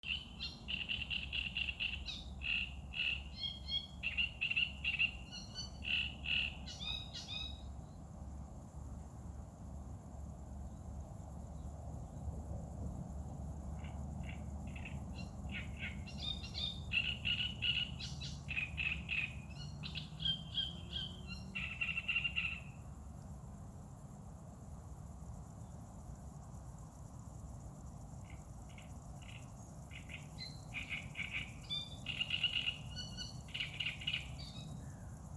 Great Reed Warbler, Acrocephalus arundinaceus
Administratīvā teritorijaRīga
StatusSinging male in breeding season